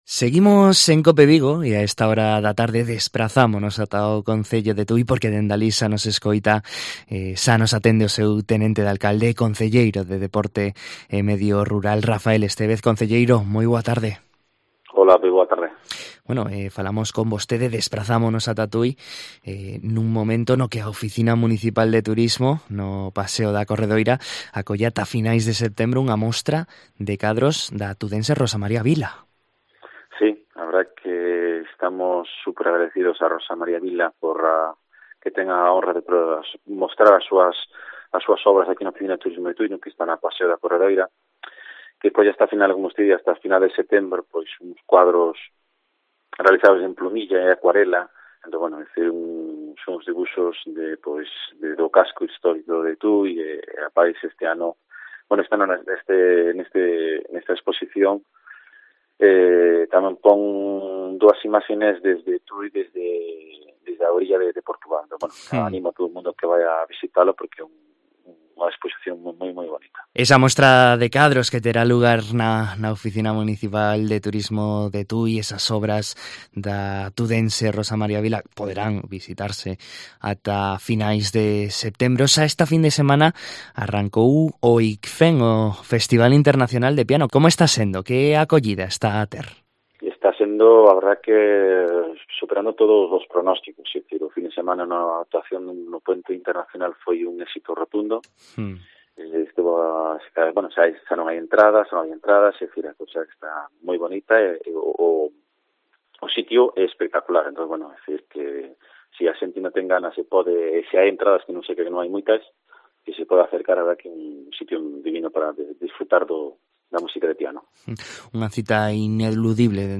En COPE Vigo coñecemos a actualidade de Tui da man do seu tenente de alcalde e concelleiro de Deportes e Medio Rural, Rafael Estevez